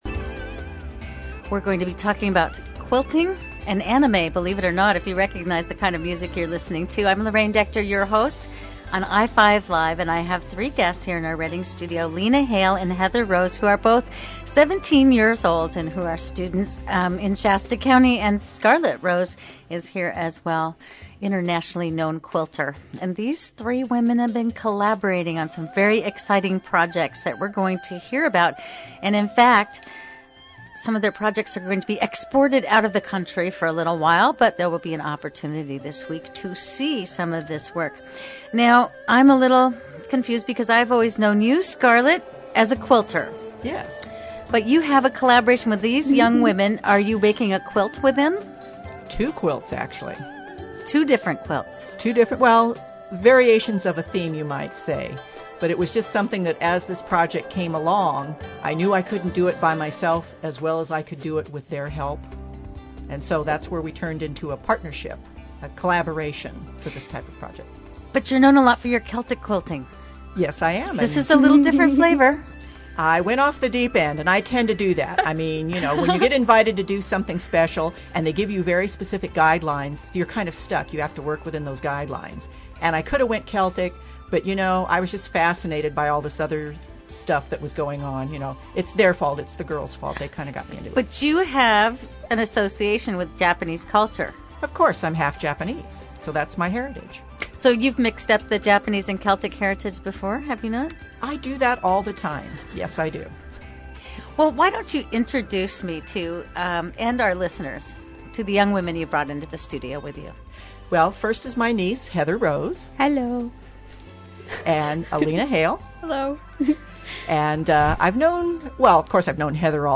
The I-5 Live show was a one hour live program that covered anime and quilting, with everyone there in the studio.
Here are MP3 files of the I-5 Live interview, broken into 5 parts because of their size.